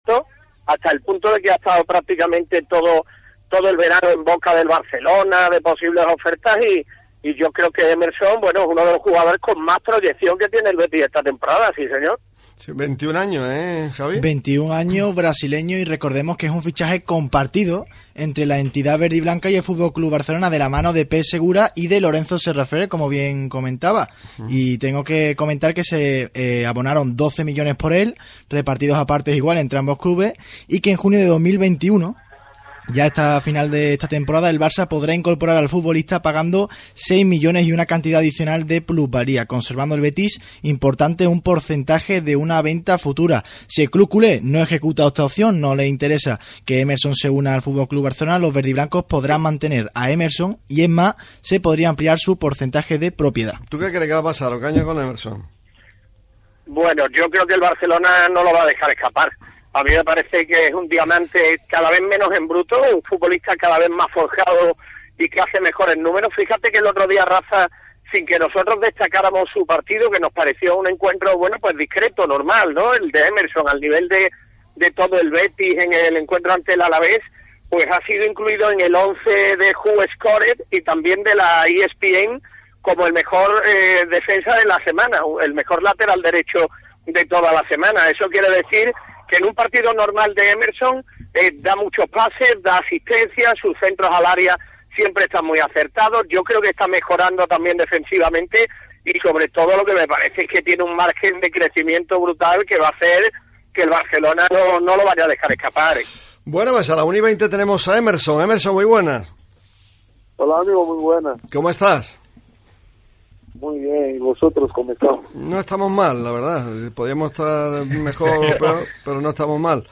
En una jugosa entrevista, Emerson ha descubierto su pasión por Daniel Alves: “Es mi ídolo.